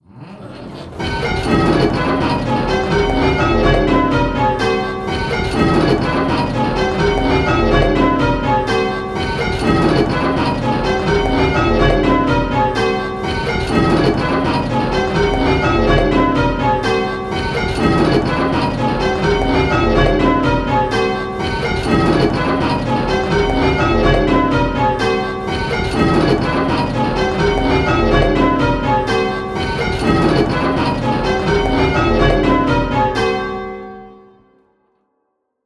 Striking 8 Bell Rounds - Pebworth Bells
Striking 8 Bell Rounds - Round 2